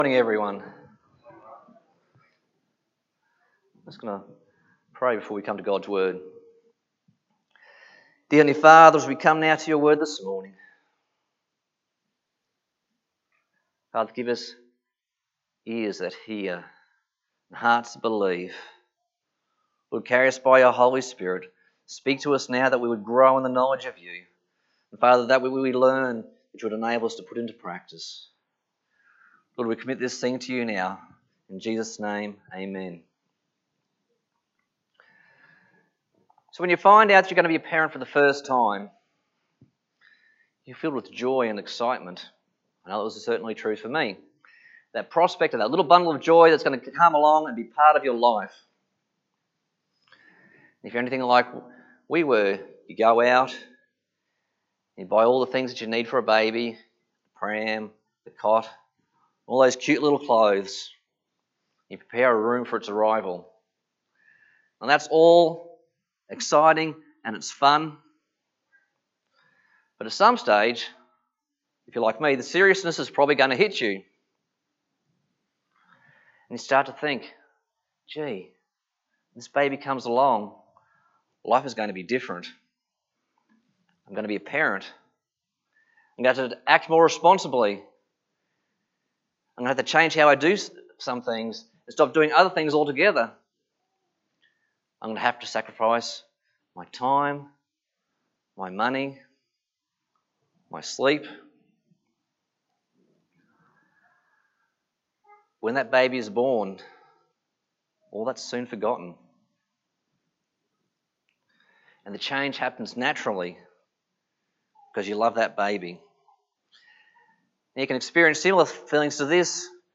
Passage: 1 Peter 1:13-21 Service Type: Sunday Morning